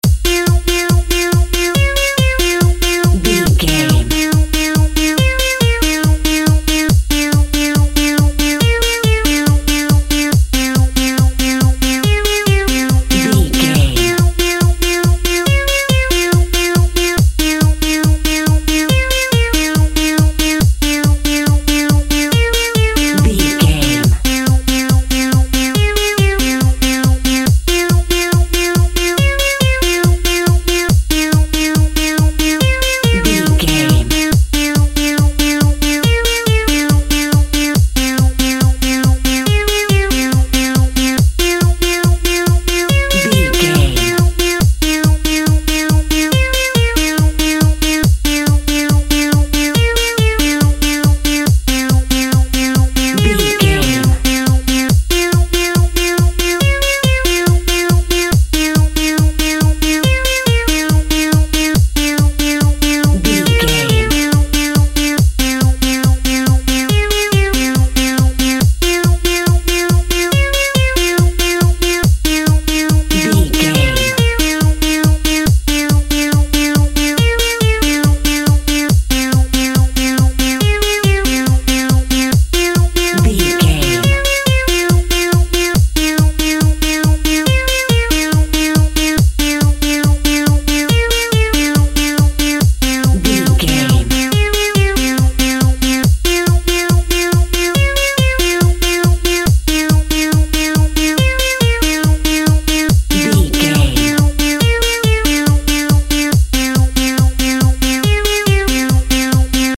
Trance Versus Techno.
Aeolian/Minor
futuristic
hypnotic
driving
energetic
drum machine
synthesiser
synth lead
synth bass